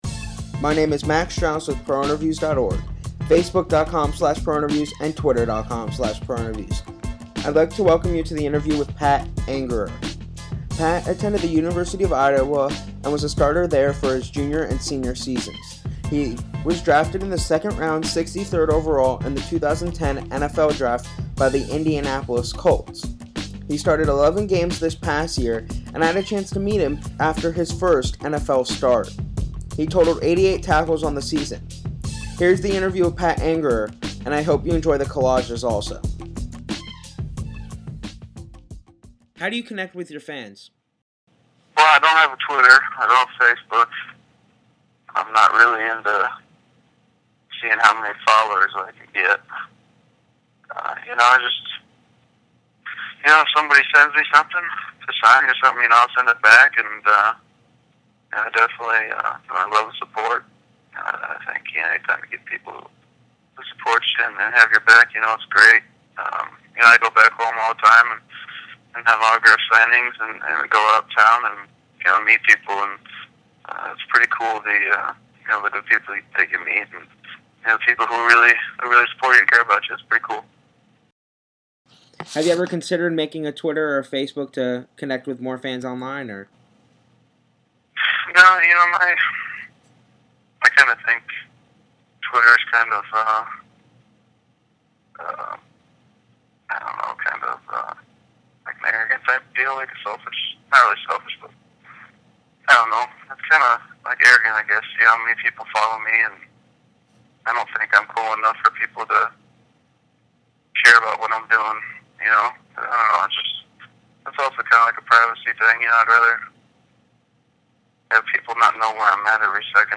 Colts MLB, Pat Angerer Interview
Check out our interview that was conducted in the spring of 2011.
interview-with-pat-angerer.mp3